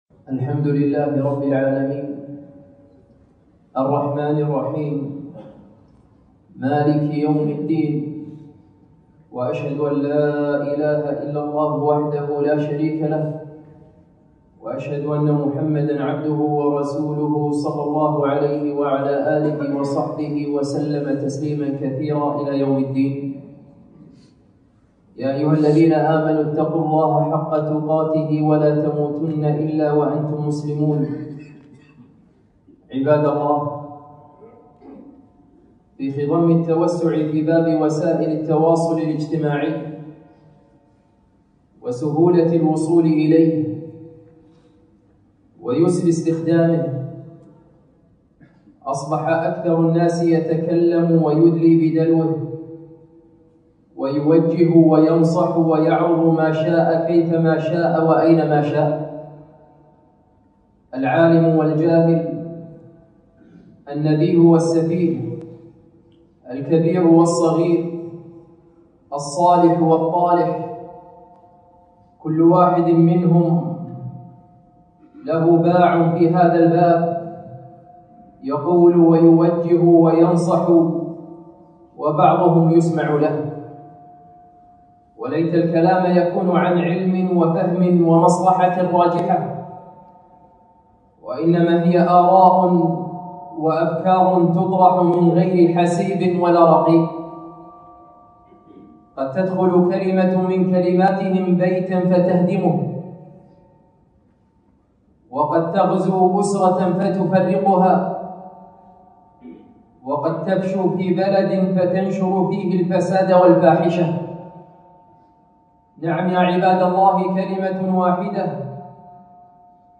خطبة - لا حاجة للمرأة إلى الزوج !!